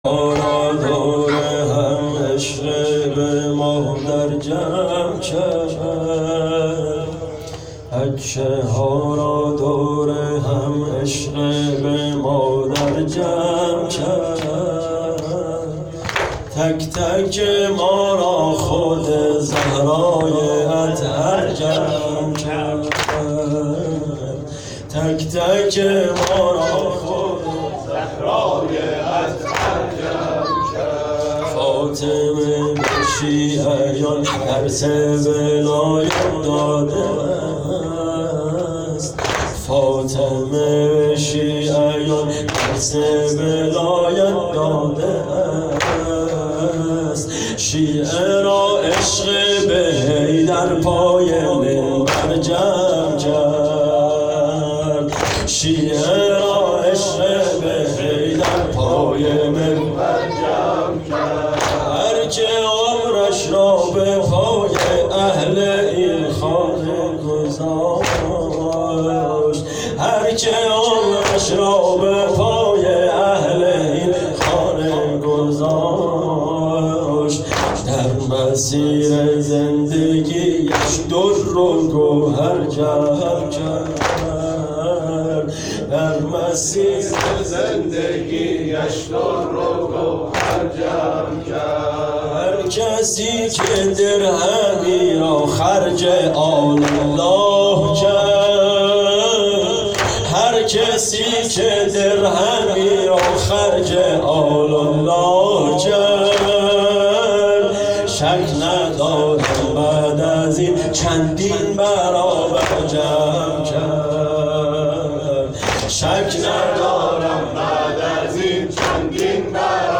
خیمه گاه - هیئت محبان المهدی(عج)آمل - شب دوم شهادت حضرت زهرا(س)فاطمیه دوم_بخش سوم واحد